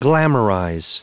Prononciation du mot glamorize en anglais (fichier audio)
Prononciation du mot : glamorize